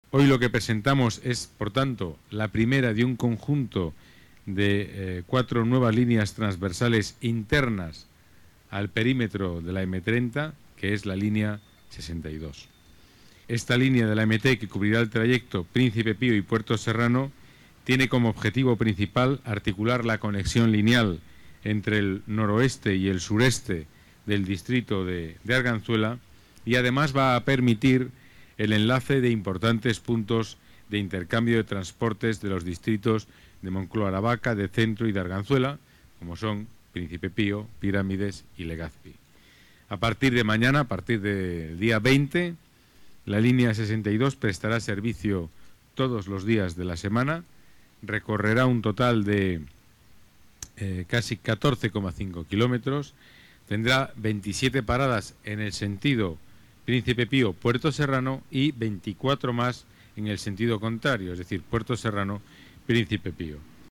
Nueva ventana:Declaraciones del delegado de Seguridad, Pedro Calvo